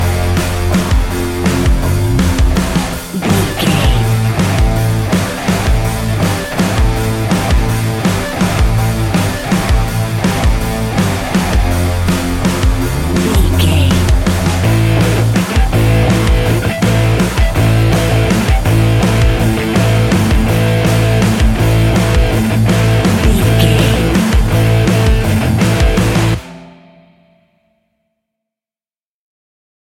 Ionian/Major
hard rock
guitars
heavy metal
instrumentals